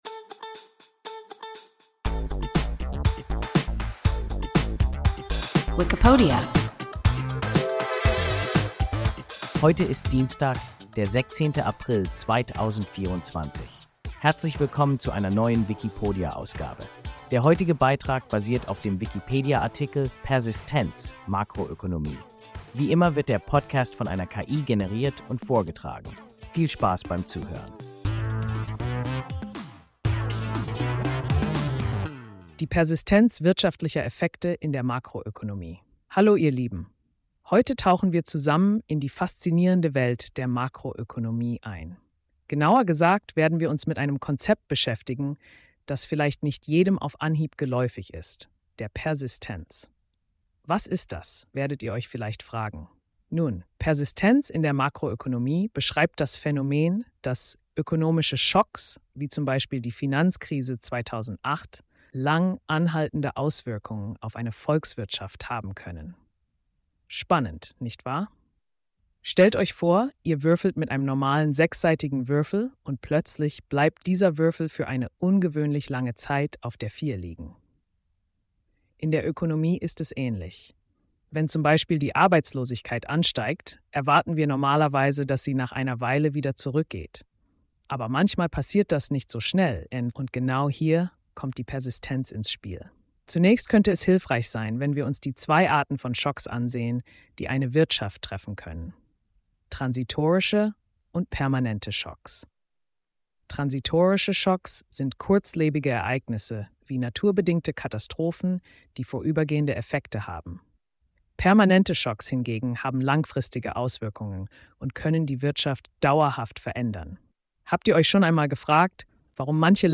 Persistenz (Makroökonomie) – WIKIPODIA – ein KI Podcast